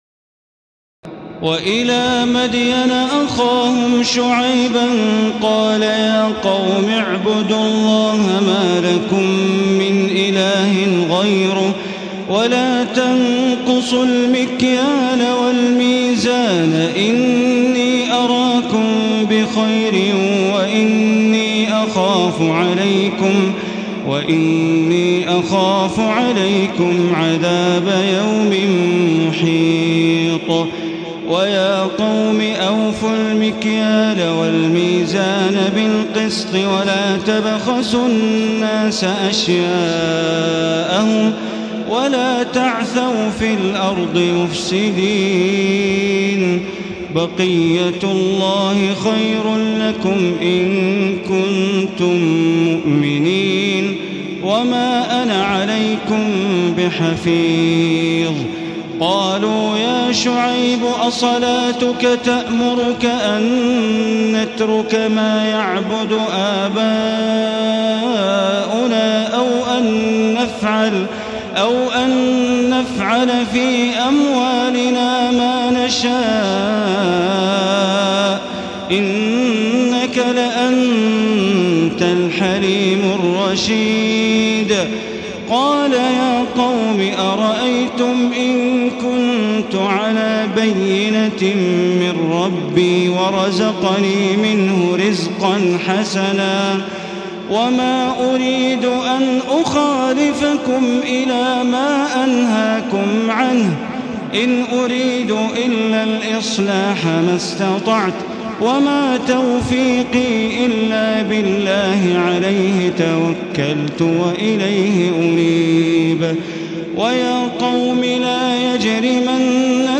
تراويح الليلة الثانية عشر رمضان 1435هـ من سورتي هود (84-123) و يوسف (1-57) Taraweeh 12 st night Ramadan 1435H from Surah Hud and Yusuf > تراويح الحرم المكي عام 1435 🕋 > التراويح - تلاوات الحرمين